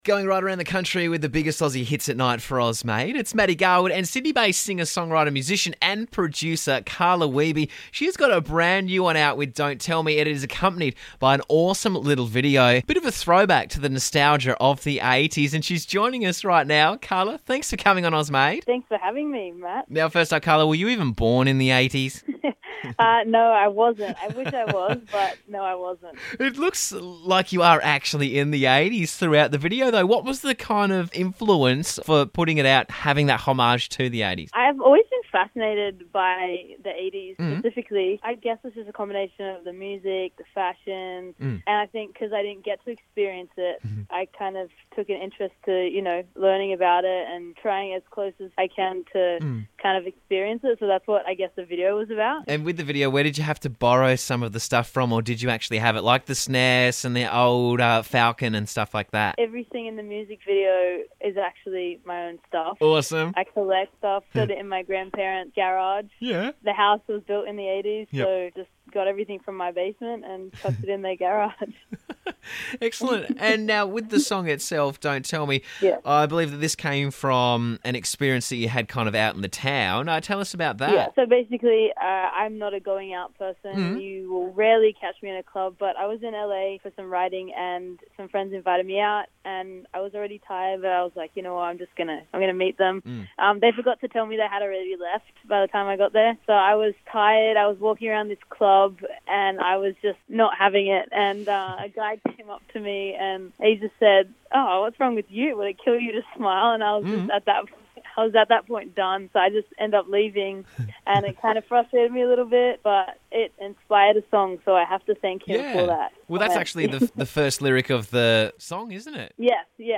Sydney-based singer, songwriter, musician and producer
merges current pop melodies with an edgy 80’s nostalgia